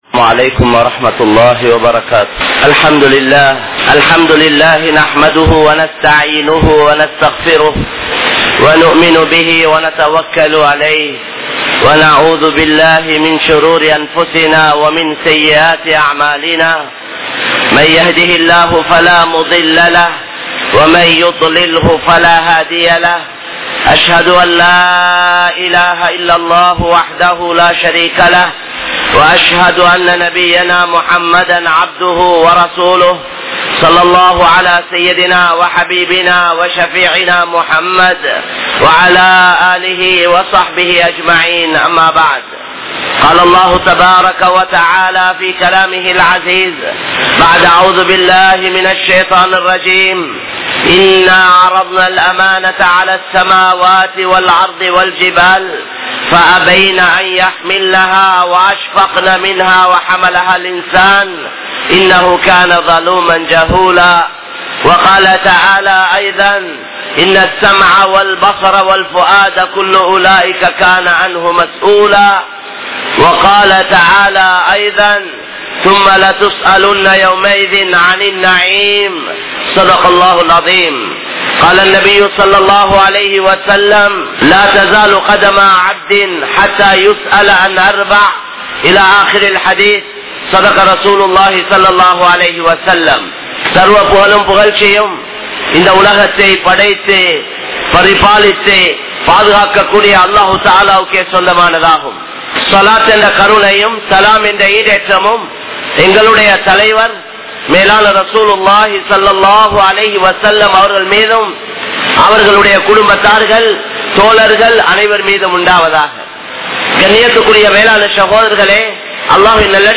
Veenaana Thodarfuhal | Audio Bayans | All Ceylon Muslim Youth Community | Addalaichenai